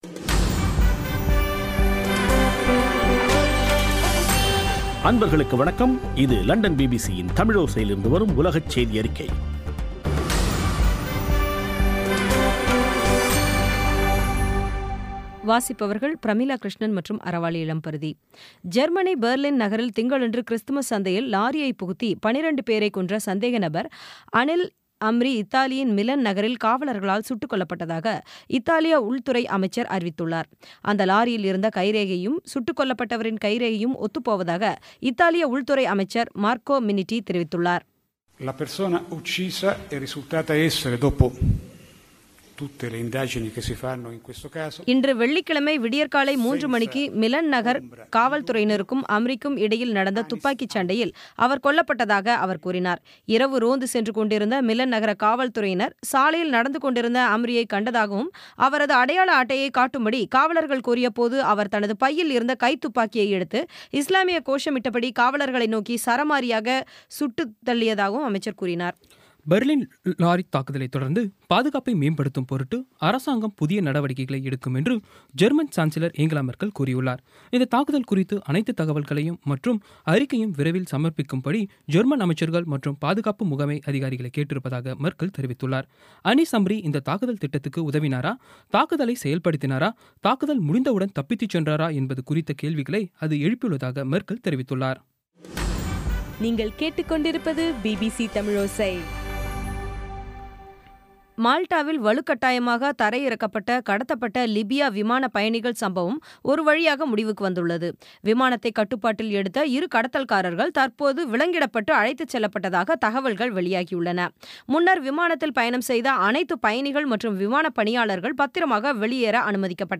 பி பி சி தமிழோசை செய்தியறிக்கை (23/12/16)